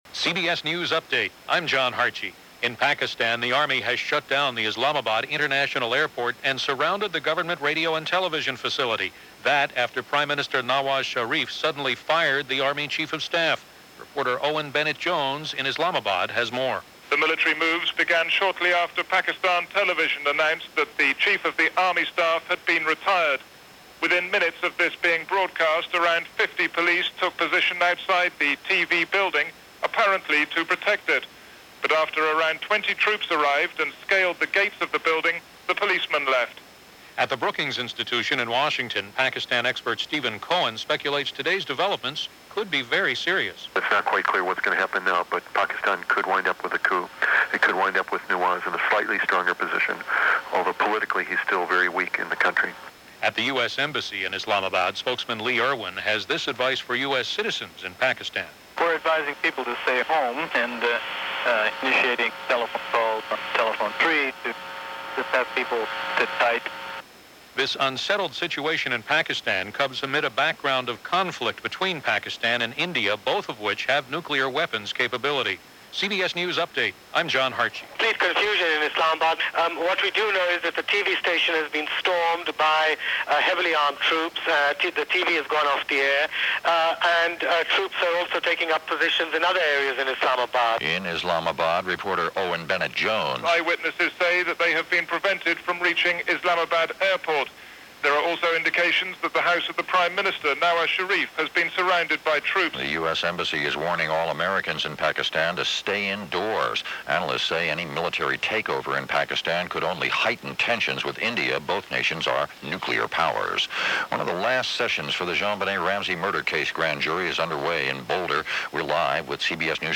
CBS News Update + CBS Hourly Newscasts